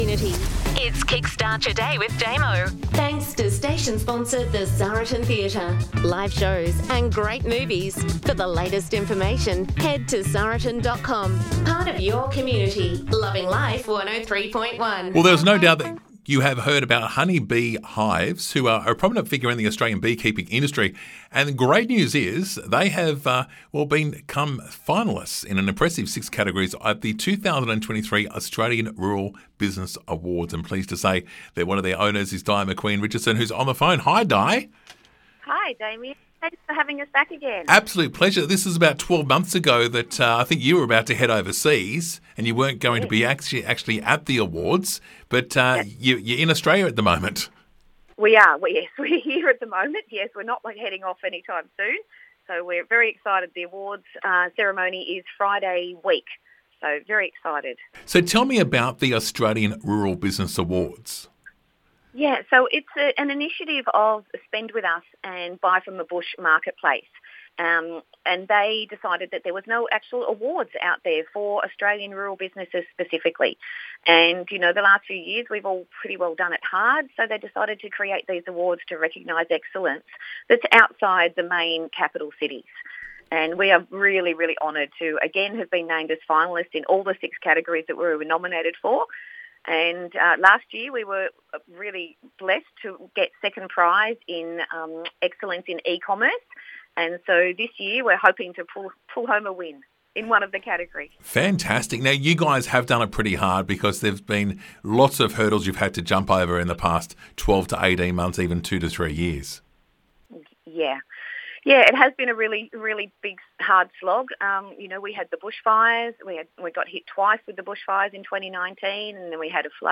Radio Interview: Loving Life FM103.1